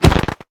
Minecraft Version Minecraft Version snapshot Latest Release | Latest Snapshot snapshot / assets / minecraft / sounds / entity / player / attack / crit1.ogg Compare With Compare With Latest Release | Latest Snapshot